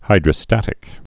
(hīdrə-stătĭk) also hy·dro·stat·i·cal (-ĭ-kəl)